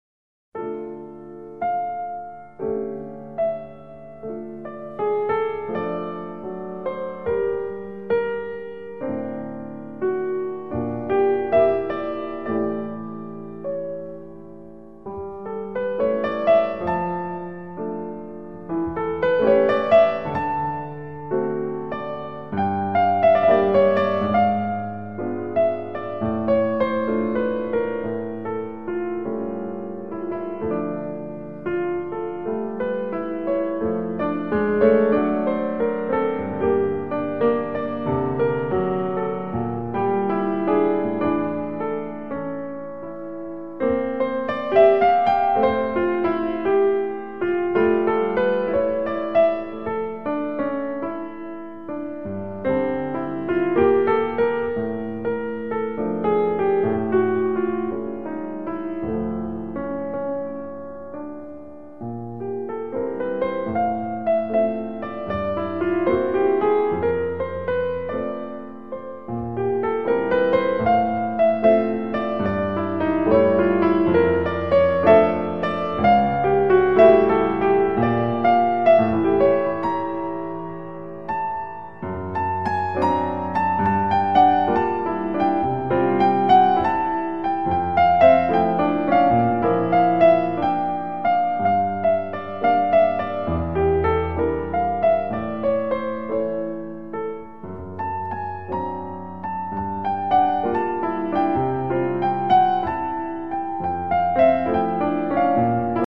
У п’єсі „Осіння пісня” лунає і тиха розповідь, і стрімкий танок.
Як динамічні відтінки forte і piano допомогли композиторові передати настрої осені?